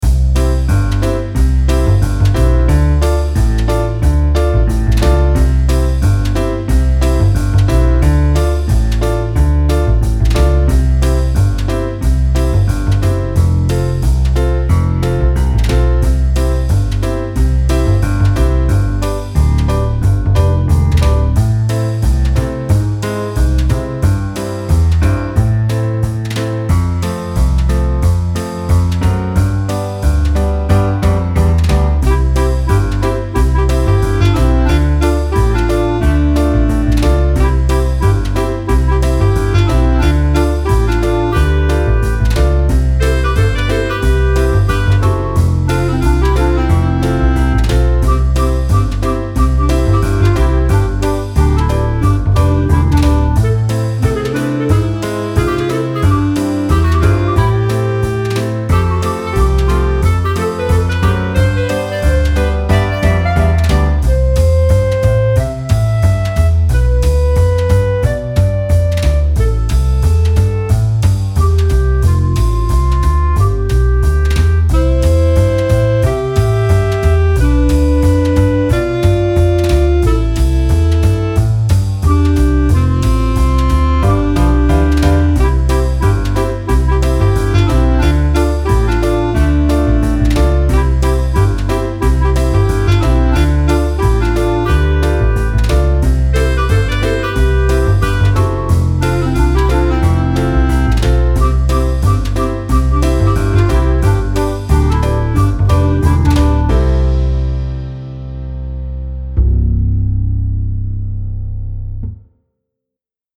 Style Style Oldies
Mood Mood Bright, Relaxed
Featured Featured Bass, Drums, Piano +2 more
BPM BPM 180